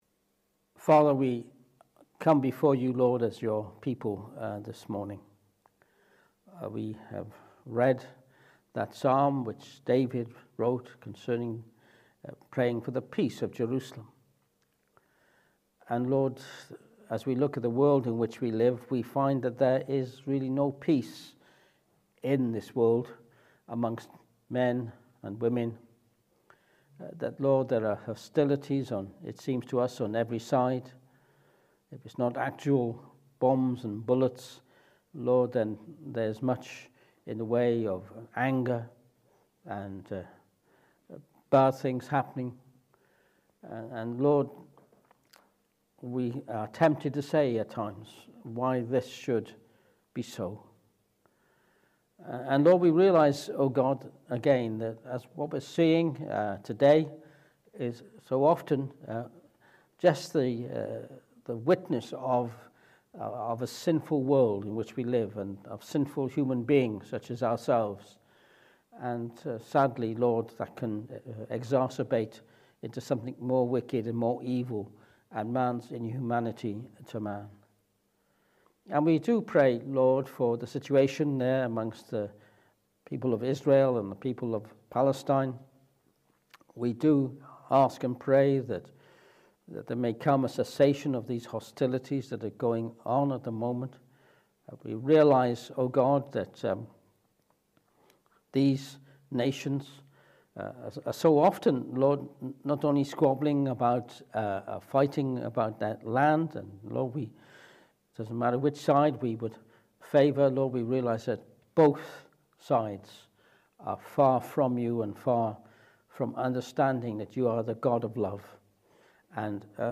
For this morning's sermon we will be turning to Mark 11:12-24 and the illustration of the barren fig tree. What can the illustration of the fig tree teach us about barren religion and prayer?
Service Type: Morning Service